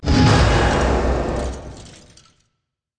playerdies_4.ogg